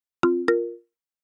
Вы можете слушать онлайн и скачать бесплатно в mp3 рингтоны входящих звонков, мелодии смс-уведомлений, системные звуки и другие аудиофайлы.